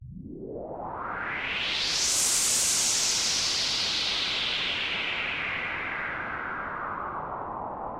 FX白噪声
Tag: 127 bpm Electronic Loops Fx Loops 2.54 MB wav Key : Unknown